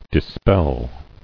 [dis·pel]